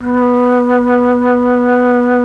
RED.FLUT1  5.wav